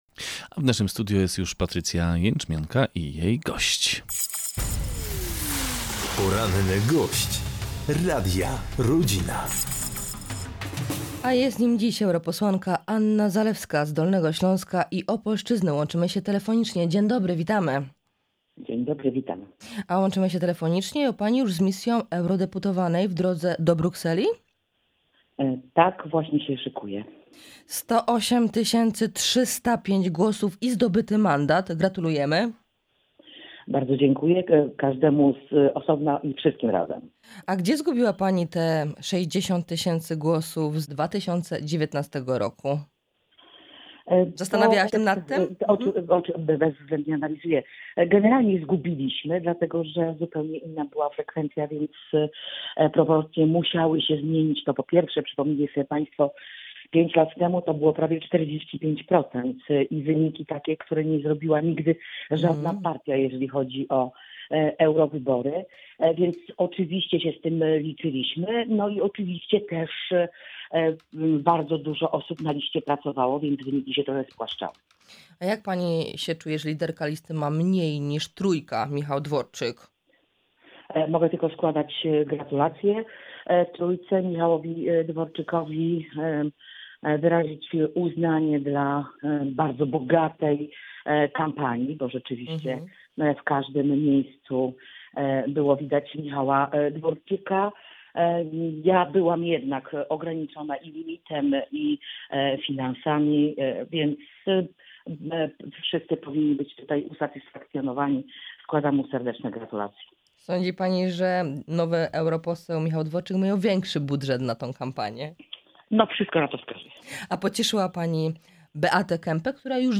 Na naszej liście bardzo dużo osób pracowało, stąd spłaszczenie wyników, mówi eurodeputowana Anna Zalewska.